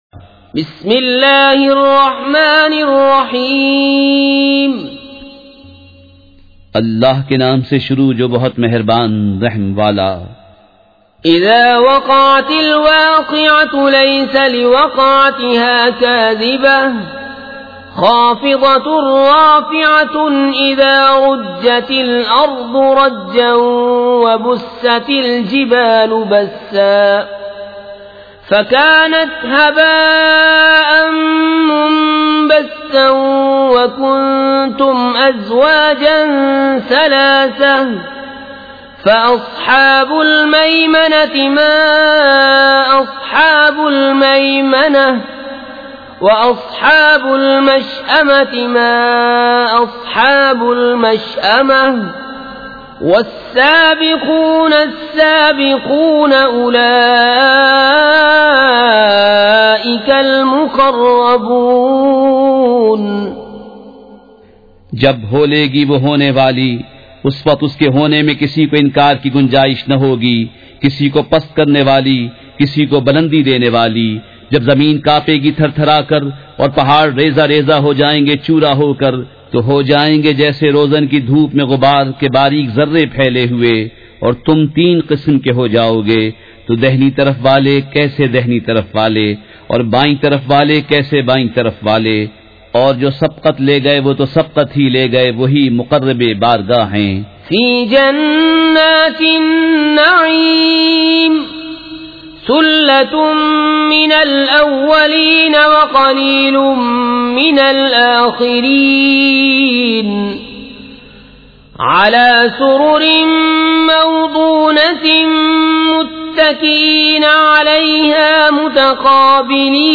سورۃ الواقعۃ مع ترجمہ کنزالایمان ZiaeTaiba Audio میڈیا کی معلومات نام سورۃ الواقعۃ مع ترجمہ کنزالایمان موضوع تلاوت آواز دیگر زبان عربی کل نتائج 2122 قسم آڈیو ڈاؤن لوڈ MP 3 ڈاؤن لوڈ MP 4 متعلقہ تجویزوآراء